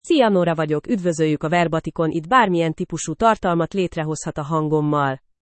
NoraFemale Hungarian AI voice
Nora is a female AI voice for Hungarian (Hungary).
Voice sample
Listen to Nora's female Hungarian voice.
Nora delivers clear pronunciation with authentic Hungary Hungarian intonation, making your content sound professionally produced.